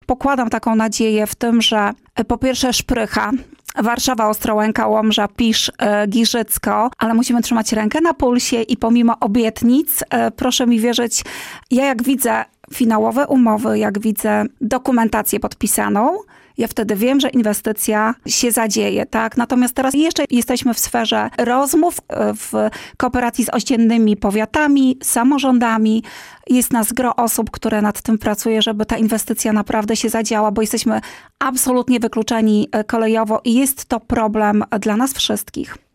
Aby rozwijać region musimy inwestować w młodych ludzi i przedsiębiorców- mówiła na antenie Radia Nadzieja Anna Gawrych, wicestarosta łomżyński. Podkreślała, że kluczem do sukcesu jest powrót kolei, o co walczą nie tylko władze powiatu łomżyńskiego, ale także samorządowcy z regionu: